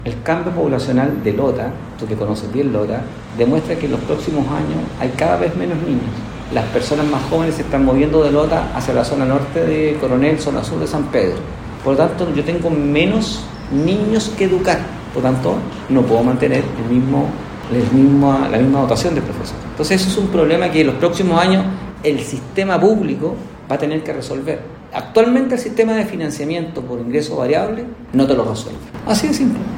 En conversación con Radio El Carbón, la autoridad se refirió a la herencia recibida por los municipios quienes por más de 40 años administraron la educación pública, donde pese a la baja de matrícula -dada la oferta educativa en los sistemas particulares y particulares subvencionados- la dotación en profesores y asistentes aumentó.